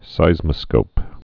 (sīzmə-skōp)